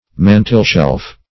Mantelshelf \Man"tel*shelf`\, n.
mantelshelf.mp3